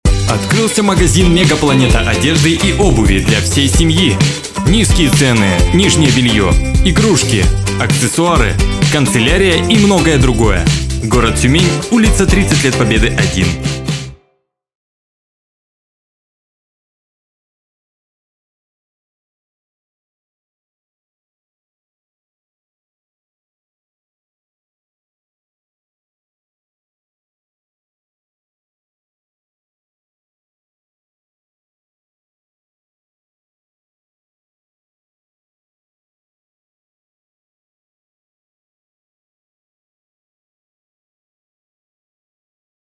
Муж, Рекламный ролик/Средний
Звуковая карта - Native instrument KOMPLETE Audio 6 Предусилитель - BEHRINGER HA 400 MICROAMP Микрофон - Behringer B-1 Звукопоглощающая комната.